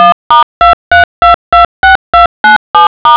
In this part of the project, the analog sound of each number in a telephone has been given.
In the end, using the array of zeros, the audio of the sounds of a desired phone number has been created and saved in audio format.
phone_number.wav